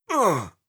06. Damage Grunt (Male).wav